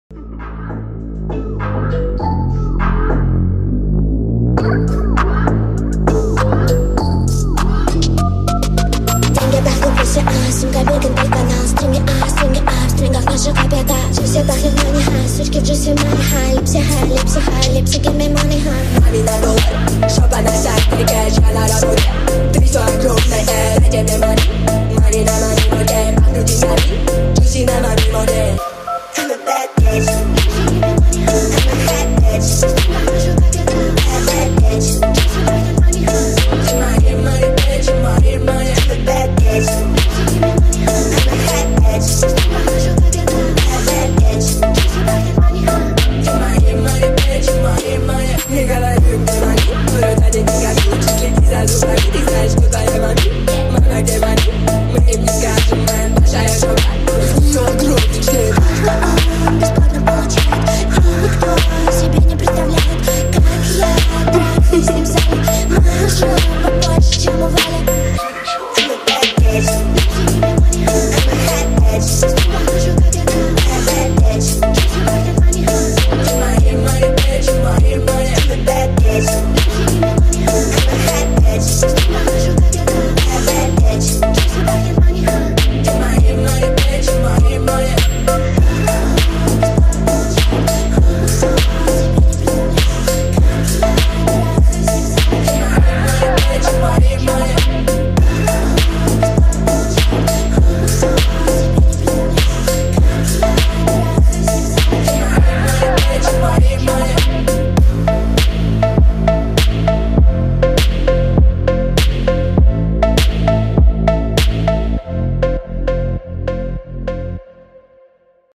это динамичная композиция в жанре фанк-хаус